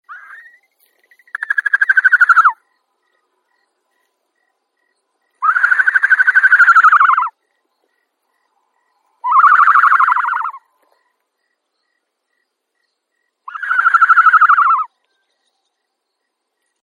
На этой странице вы найдете подборку звуков страуса – от характерного шипения до необычных гортанных криков.
Чириканье трехнедельного цыпленка-страуса